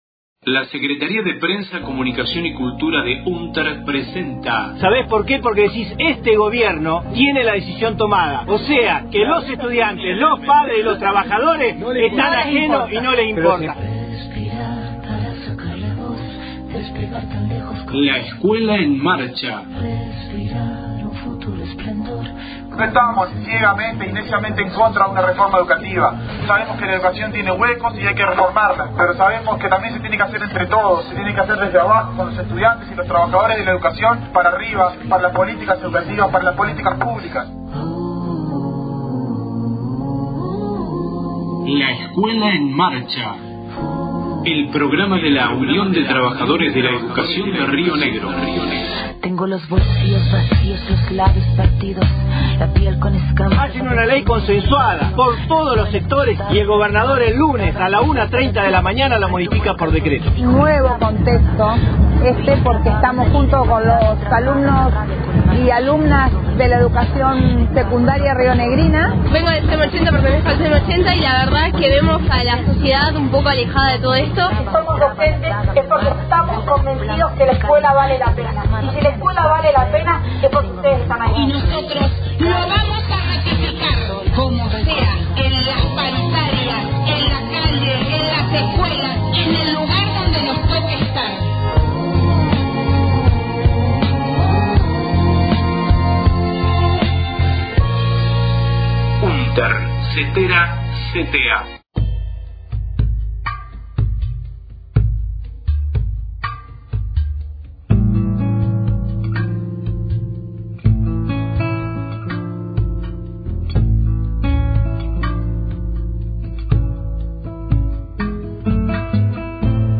Audio conferencia de prensa realizada en Roca -Fiske Menuco el 30/07/18